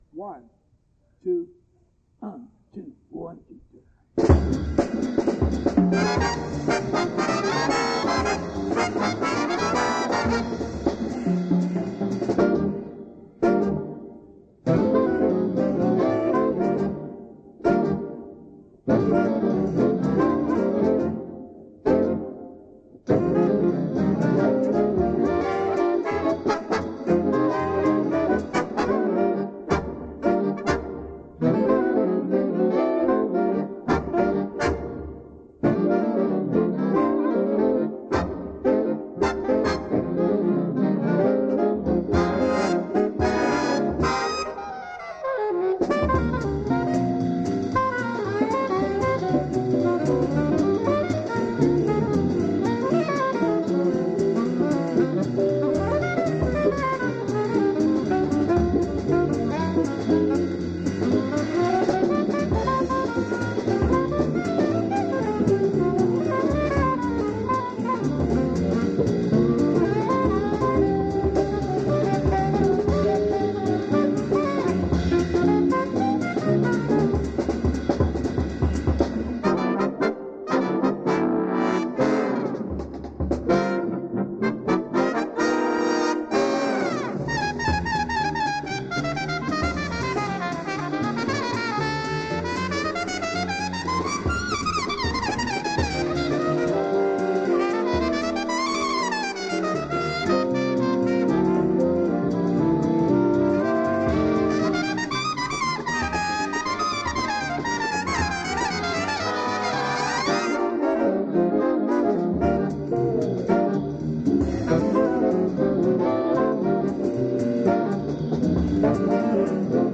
big band
lead trumpet